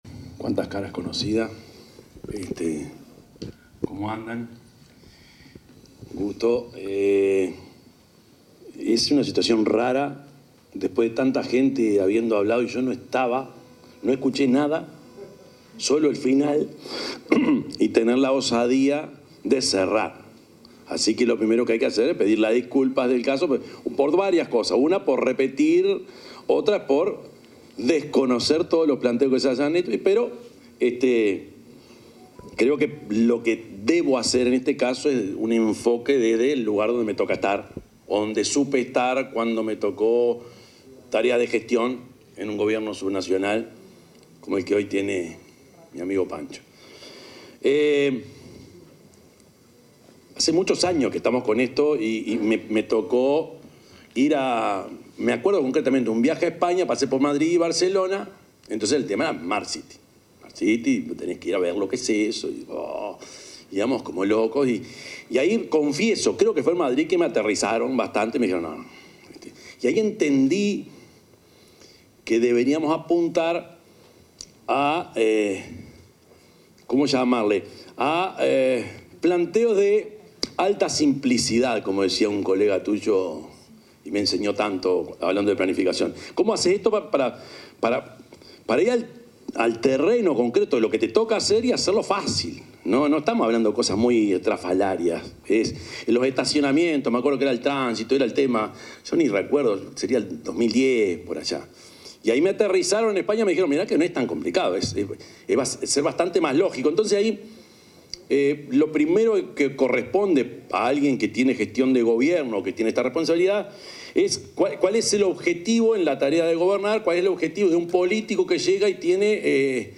Palabras del presidente Yamandú Orsi en Smart Cities Summit 2025
El presidente de la República, Yamandú Orsi, expuso en la clausura de la Smart Cities Summit 2025, que se desarrolló en el edificio World Trade Center
oratoria.mp3